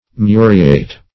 Muriate - definition of Muriate - synonyms, pronunciation, spelling from Free Dictionary
Muriate \Mu"ri*ate\, n. [See Muriatic.] (Chem.)